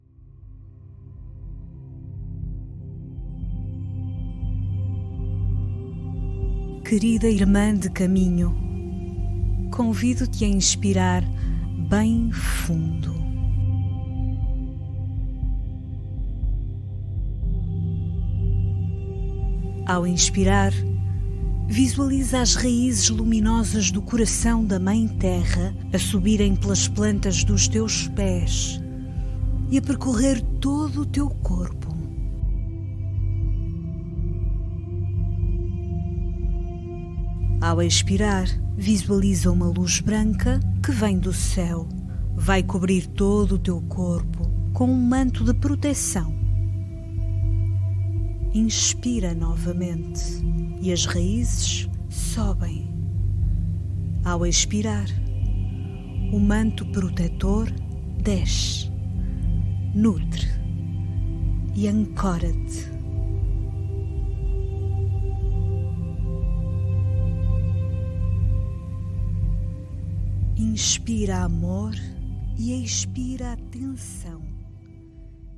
Portuguese Female Voiceover
Meditation
Meditations should be calm and peaceful.
demo_Meditacao-da-abundancia.mp3